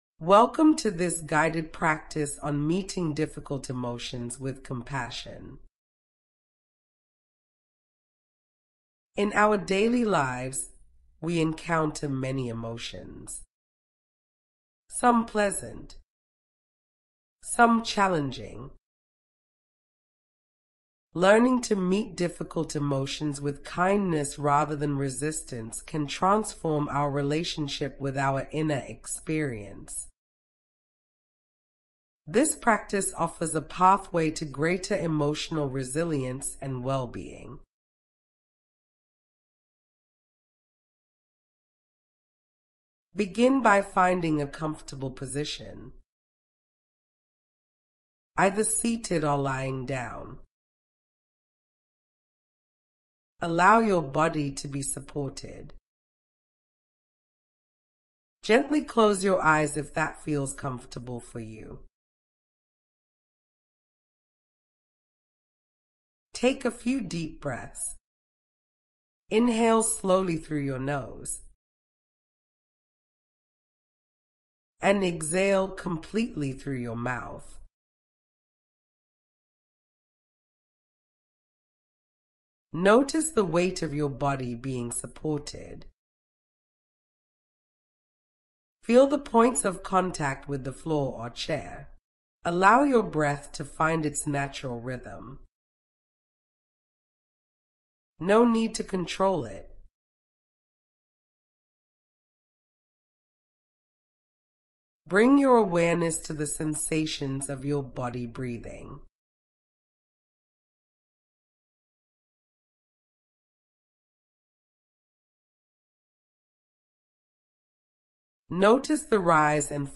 Guided-Meditation-Emotional-Awareness.mp3